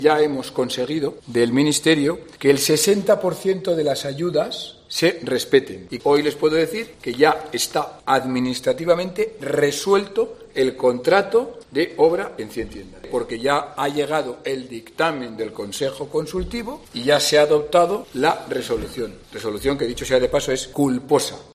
Alcalde de Logroño-Cien Tiendas